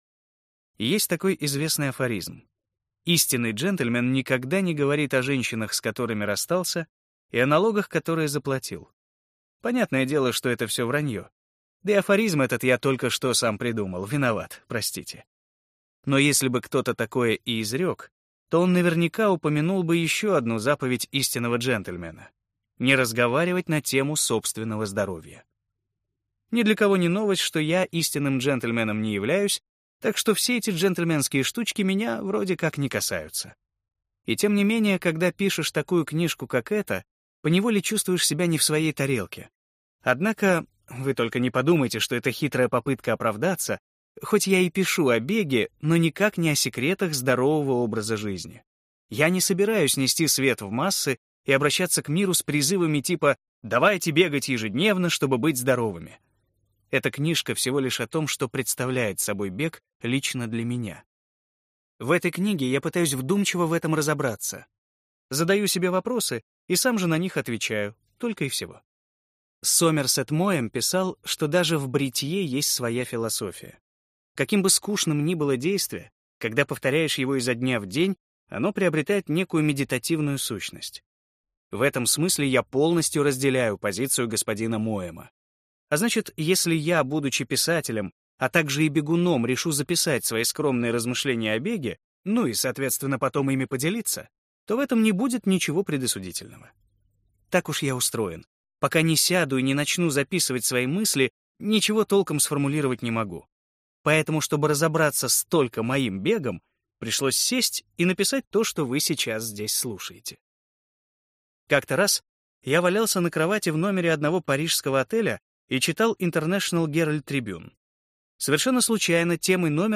Аудиокнига О чем я говорю, когда говорю о беге | Библиотека аудиокниг